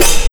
JMP130CYMB-R.wav